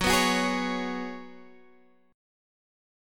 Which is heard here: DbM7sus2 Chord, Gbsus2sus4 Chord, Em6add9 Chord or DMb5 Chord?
Gbsus2sus4 Chord